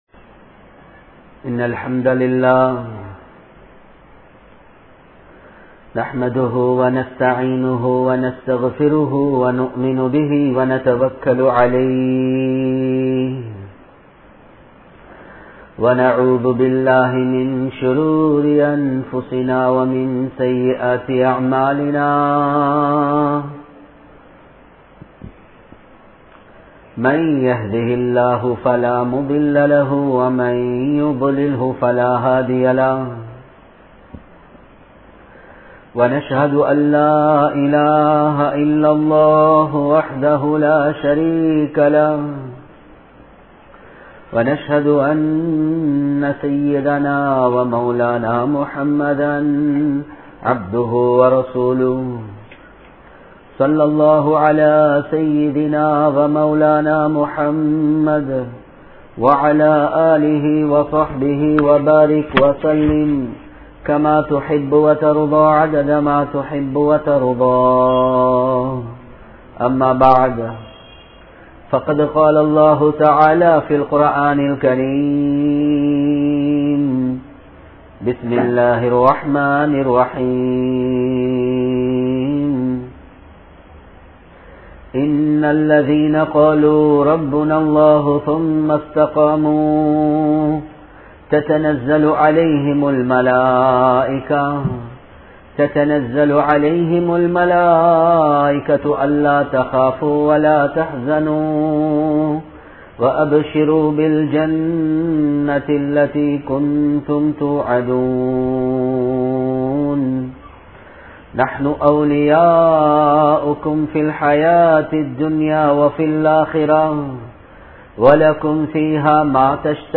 Islamiya Soolalin Avasiyam (இஸ்லாமிய சூழலின் அவசியம்) | Audio Bayans | All Ceylon Muslim Youth Community | Addalaichenai